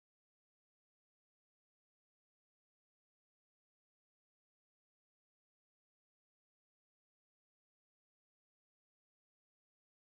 slience.mp3